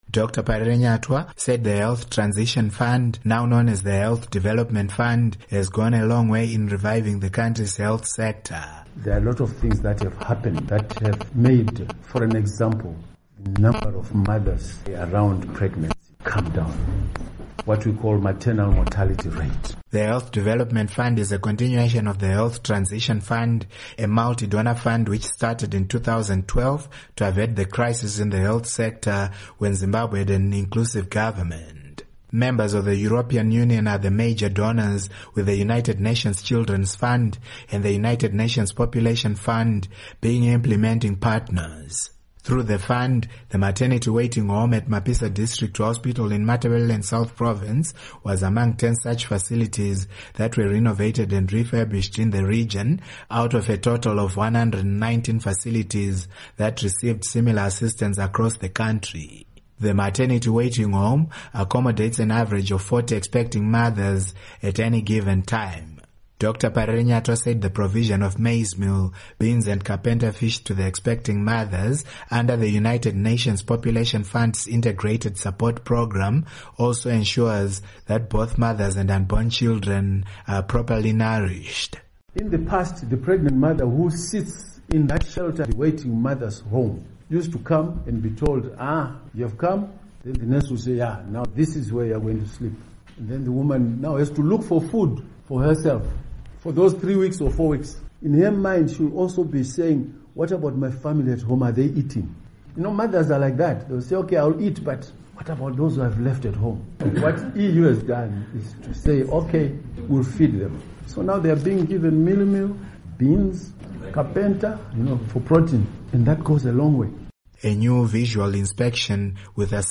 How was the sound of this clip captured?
He was responding to a question from Studio 7 on the sidelines of the tour on what government is doing to stop strikes by doctors and nurses.